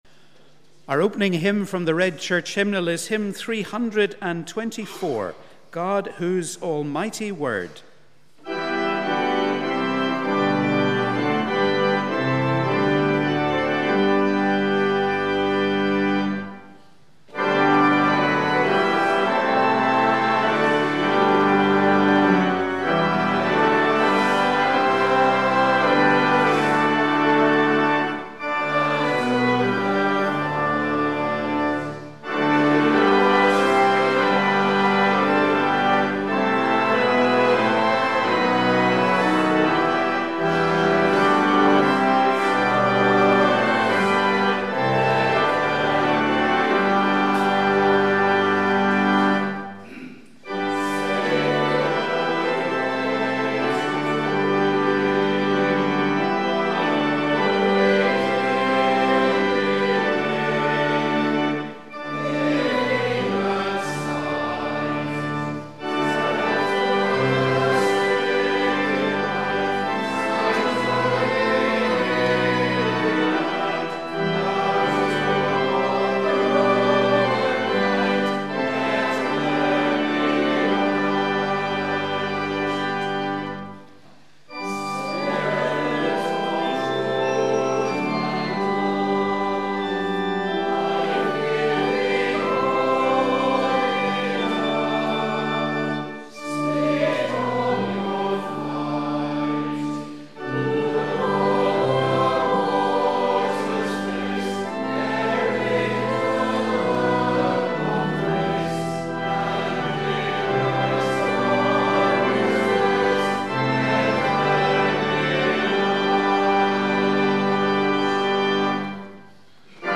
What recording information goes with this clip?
We warmly welcome you to our service of Morning Prayer on this Day of Pentecost – the day when the Church celebrates the gift of God’s Holy Spirit.